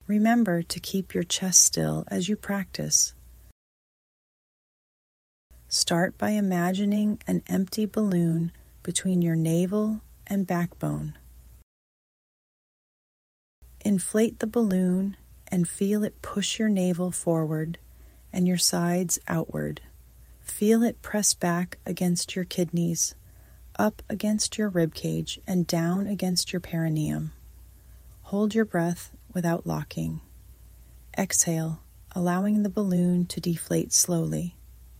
ElevenLabs-Lesson-1.mp3